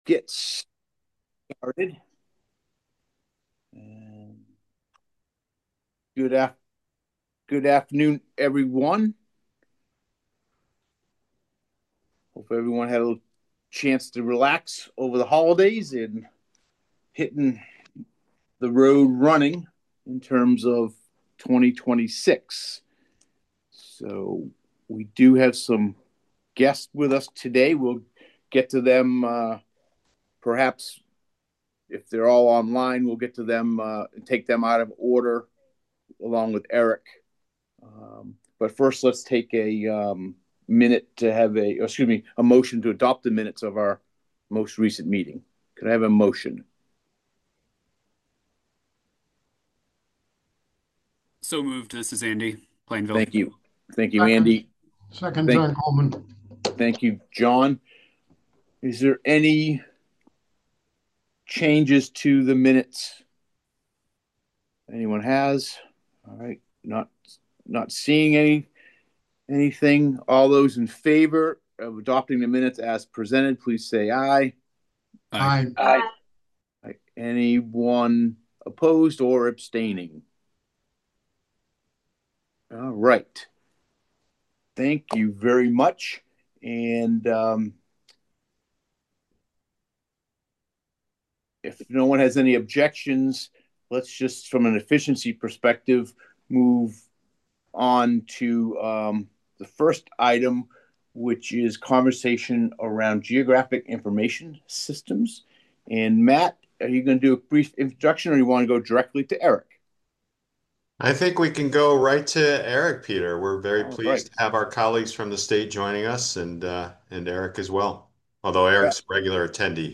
*Meeting access is remote only, consistent with provisions specified in Connecticut Public Act 22-3.
MSC 2026-01-13 Meeting Audio Recording